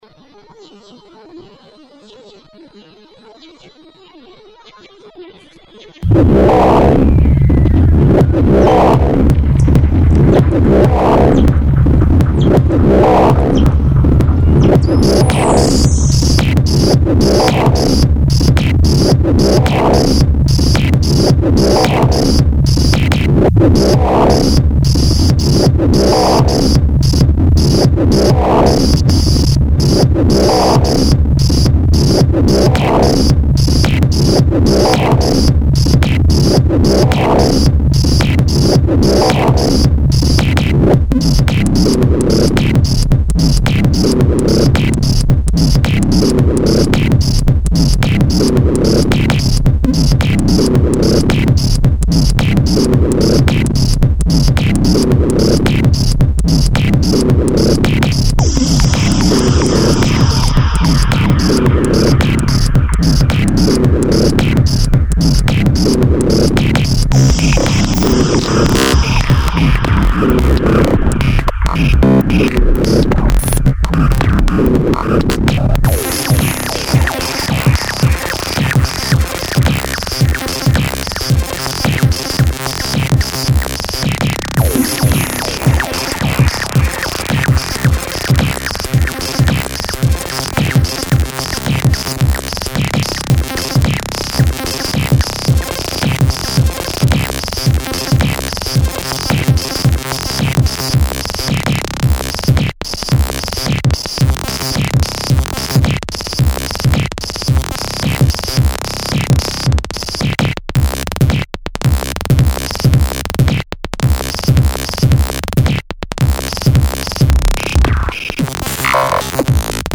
ingredients: Ross Seal
The Ross seal is able to produce a variety of complex twittering and siren-like sounds that are performed on ice and underwater, where they carry for long distances.[4] The underwater siren sound can be composed of two harmonically unrelated superimposed tones that are pulsed with the same rhythm. Uniquely, the vocalizations, whether on ice or in water, are made with a closed mouth - emitting no air.